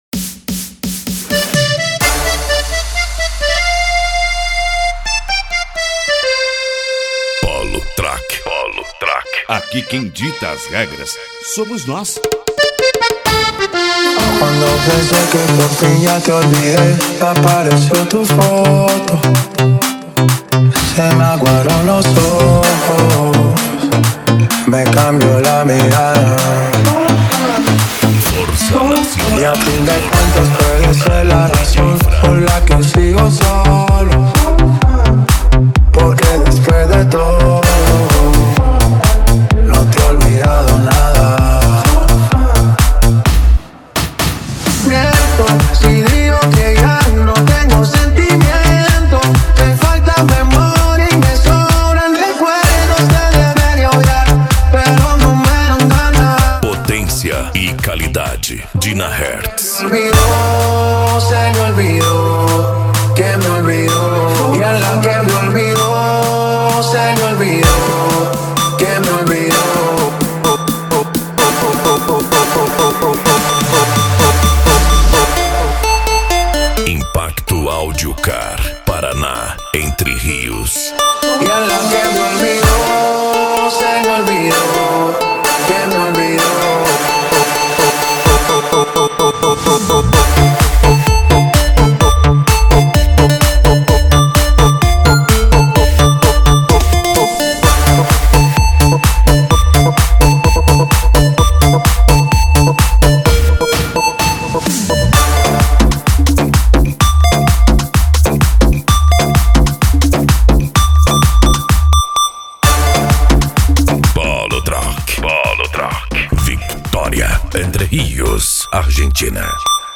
Electro House
Eletronica
Remix